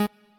left-synth_chord_last30.ogg